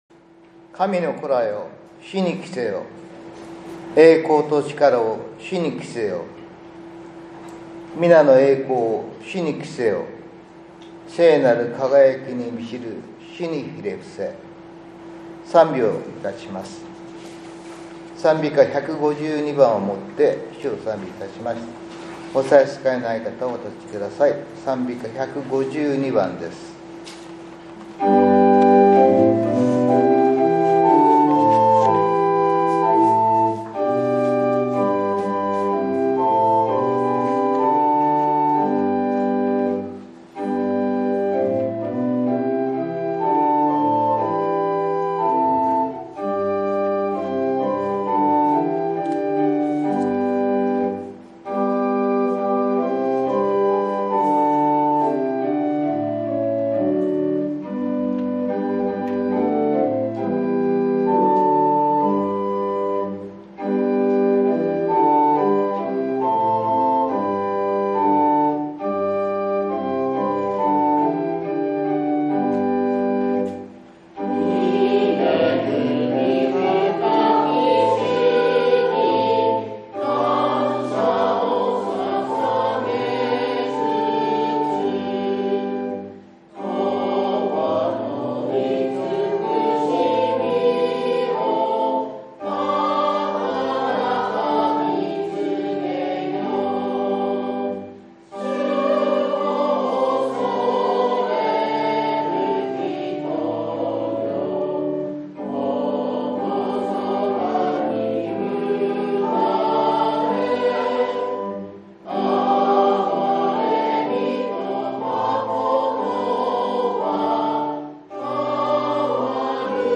８月３１日（日）主日礼拝